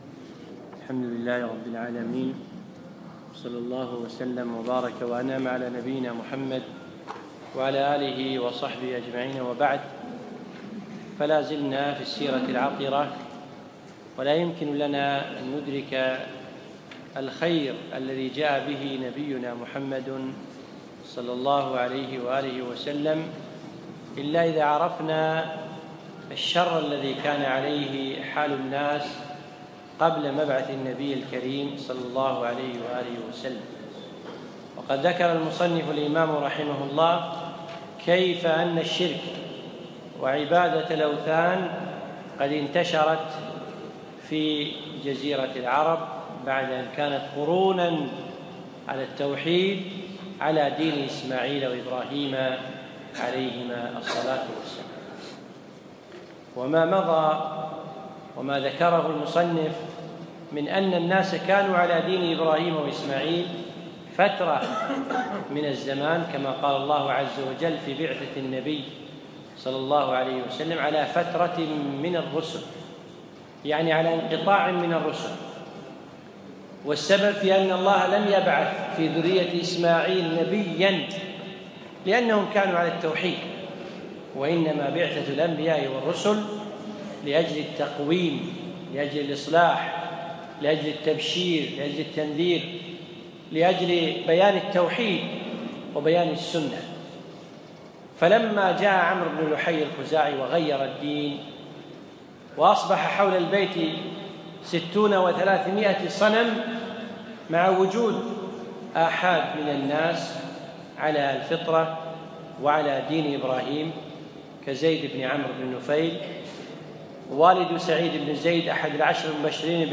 يوم الخميس 29 جمادى الأخر 1437 الموافق 7 4 2016 في مسجد فهد العجمي خيطان
الدرس الرابع